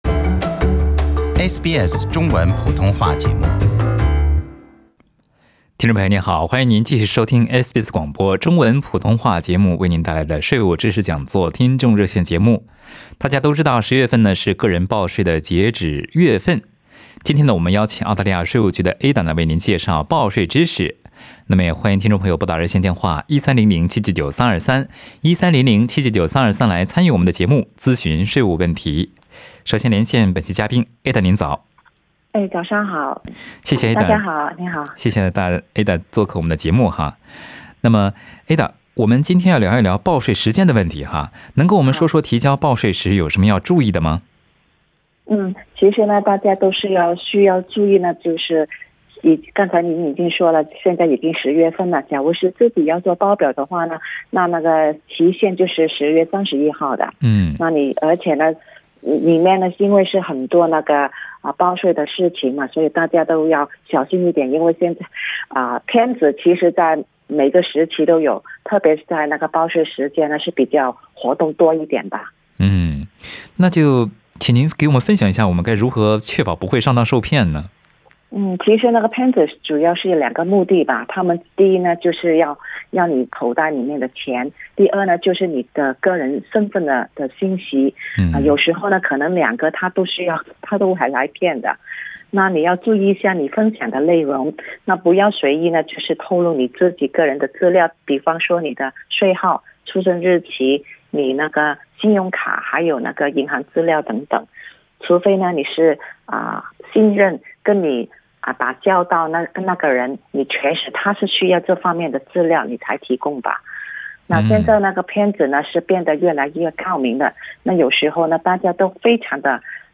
在本期《税务知识讲座》听众热线节目中，有多位听众朋友咨询了退休金存在银行是否需要报税，离婚后仍然共有私人健康保险如何报税，如何修改用车抵税信息，房产和汽车折旧抵税等具体的报税问题，请您点击收听详细的内容。